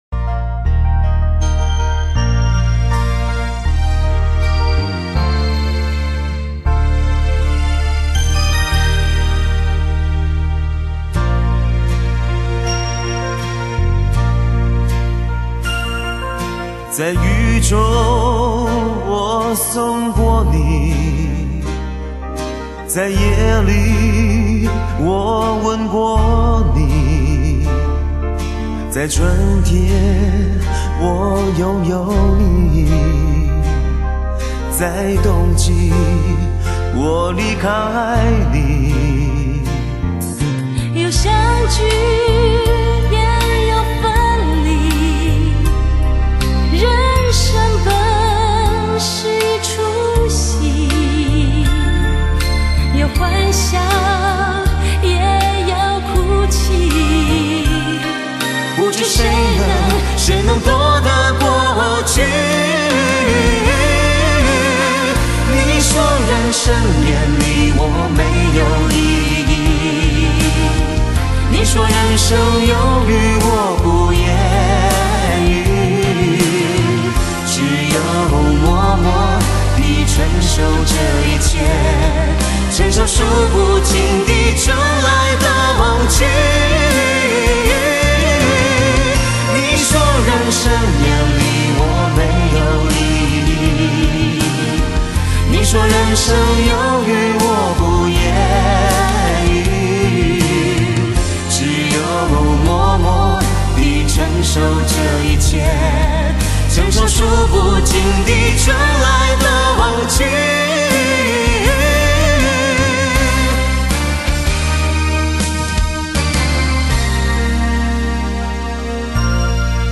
发烧天碟，经典回放，倾情演绎动人老歌
传奇真空管录音5.1DTS CD 经典珍藏
傳奇真空管錄音，美國DTS-5.1頂級編碼器，還原現場震撼體驗
發燒極品，百聽不厭;全頻段六聲道製作如同置身音樂聽之中。